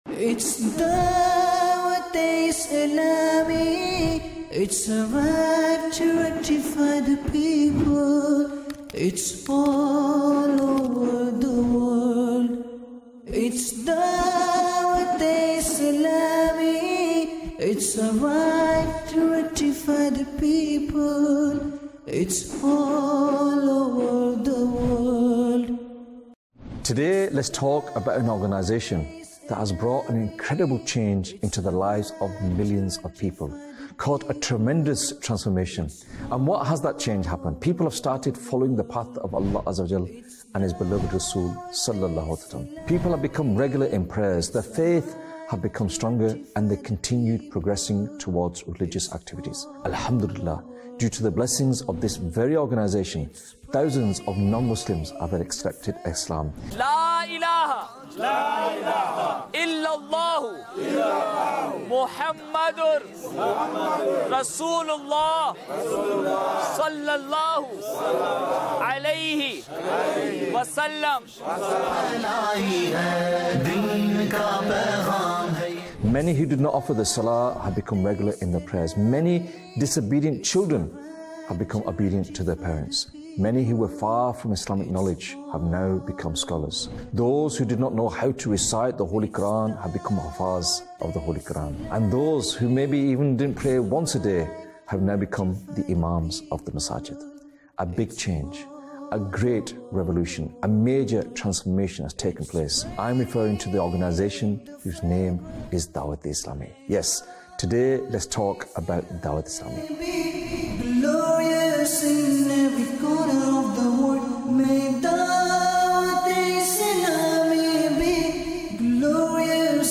Introduction To Dawateislami | Documentary 2025